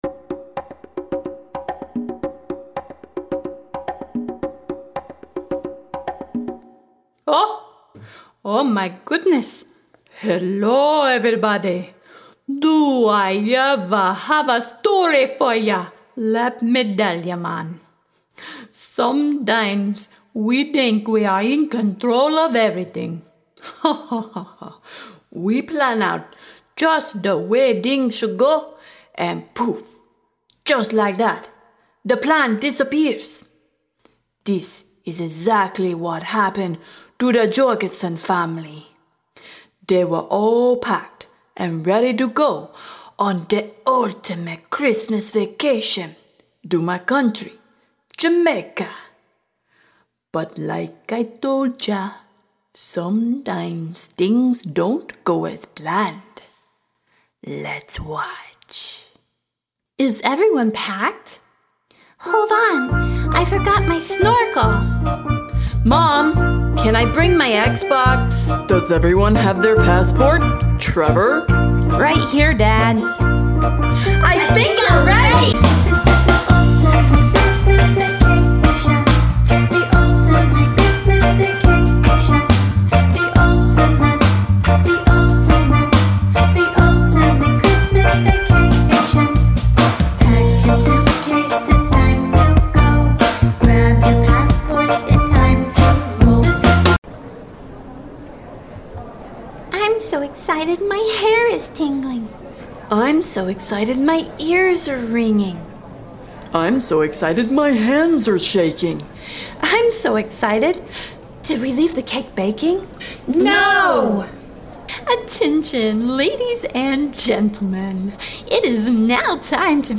A Christmas musical the whole family will enjoy.
approachable vocal ranges
catchy, but easy to learn melodies.